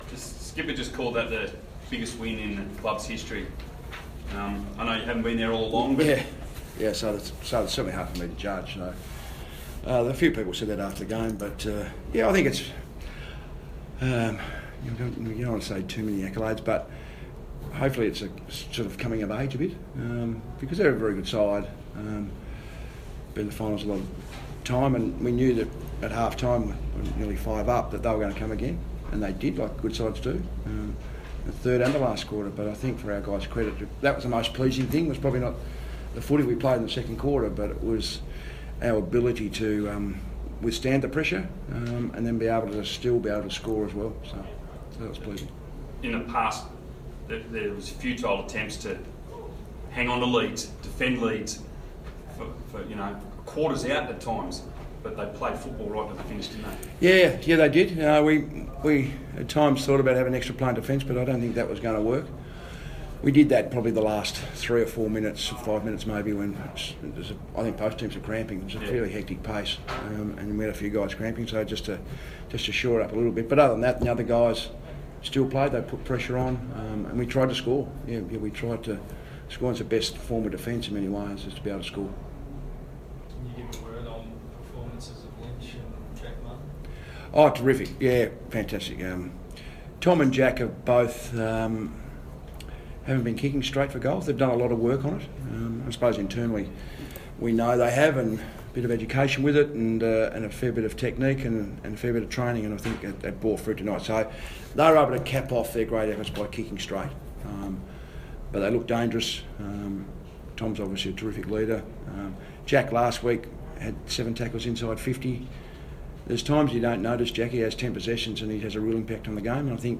Rodney Eade Post Game Press Conference